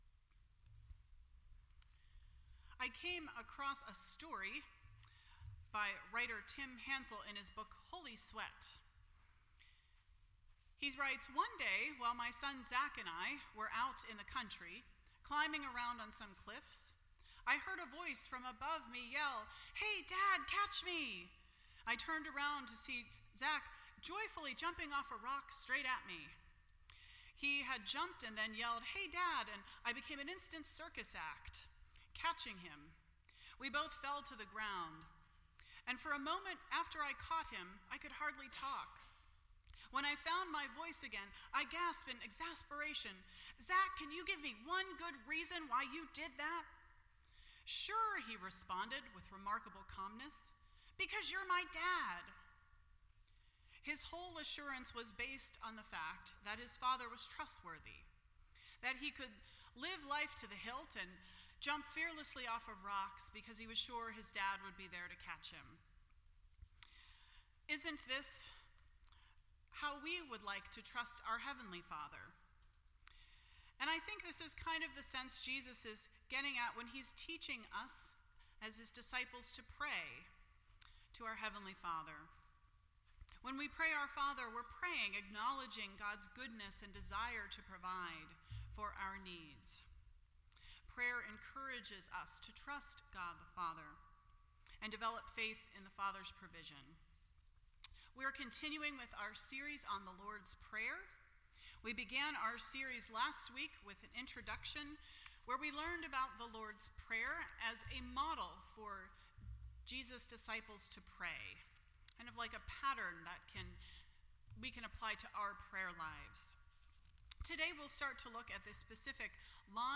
Series: The Lord's Prayer Service Type: Sunday Morning %todo_render% Share This Story